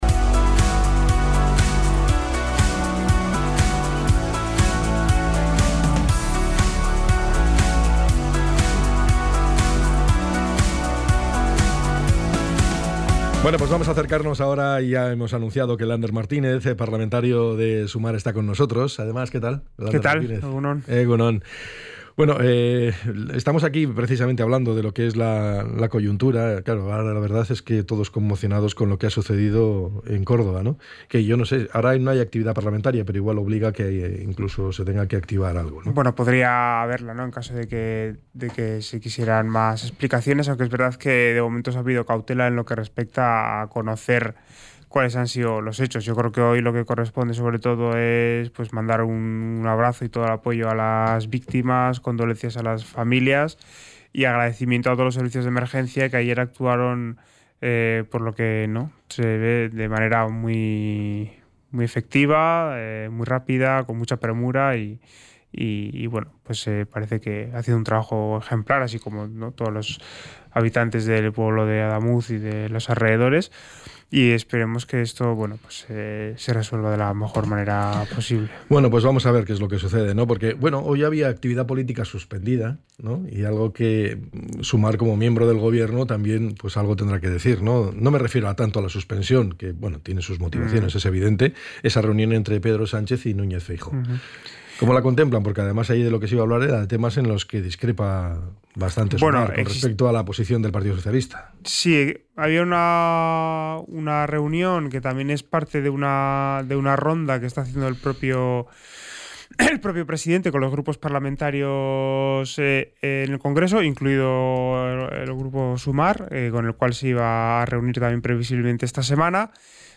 ENTREV.-LANDER-MARTINEZ.mp3